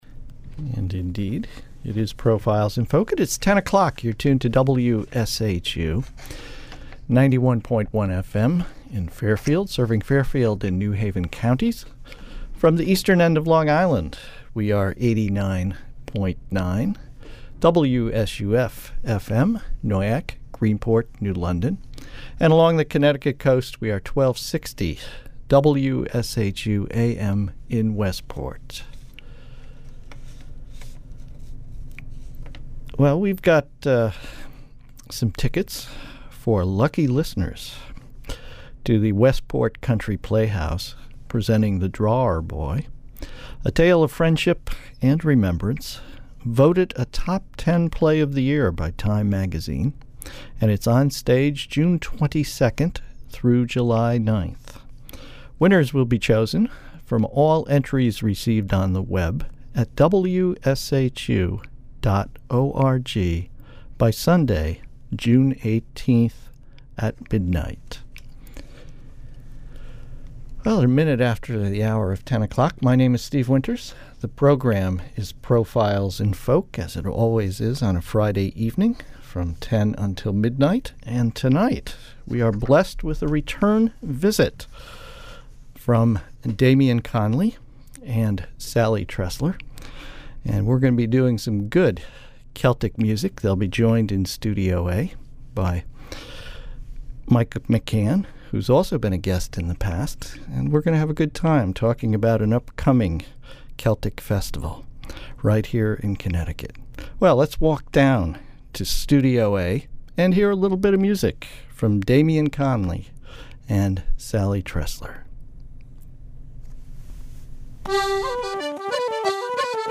Listen to the live portion of the show
accordion and melodeon player
Irish flute player